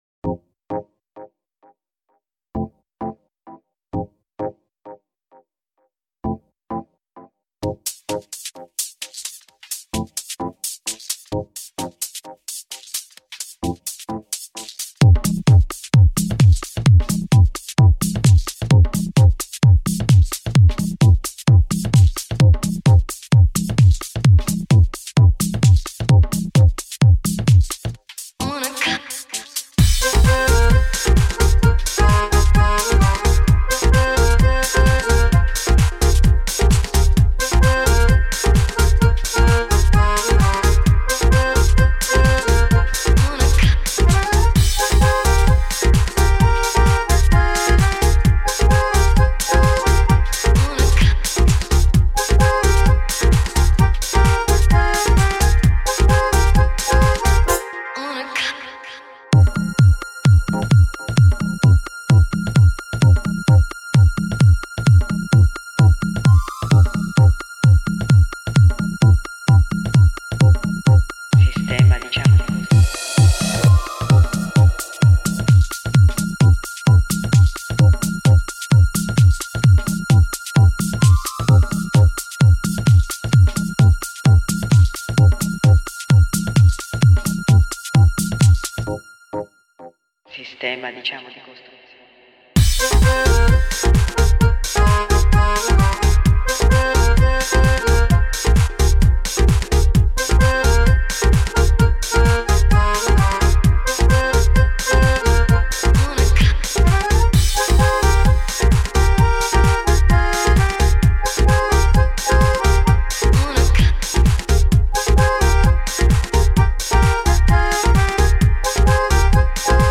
探戈轻风情